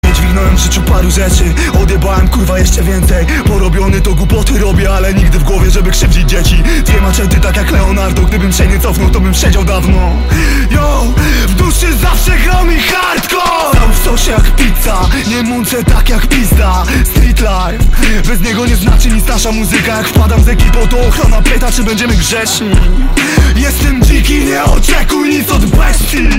Kategoria Rap/Hip Hop